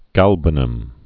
(gălbə-nəm, gôl-)